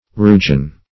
Search Result for " rugine" : The Collaborative International Dictionary of English v.0.48: Rugine \Ru"gine\, n. [F.]